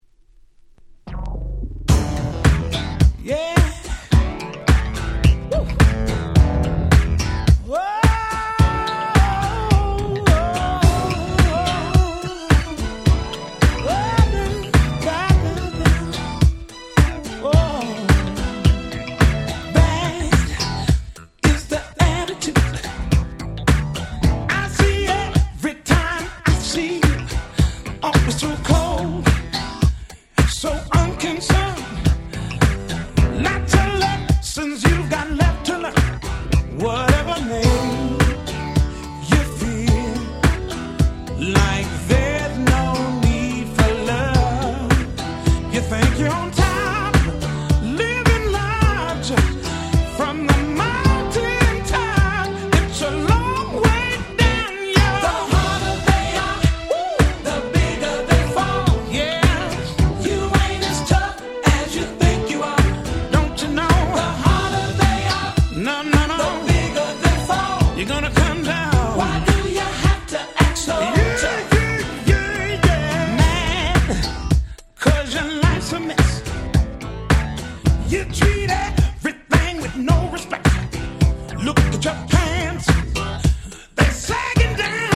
94' Nice R&B EP !!